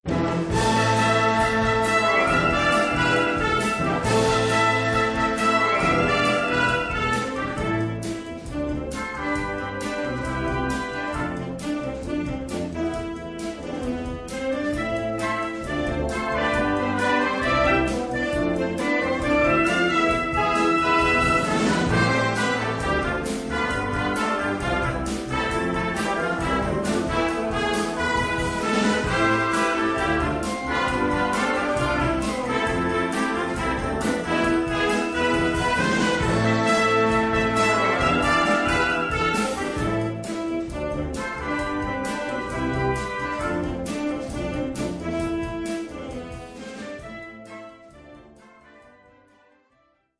Gattung: Poptitel
Besetzung: Blasorchester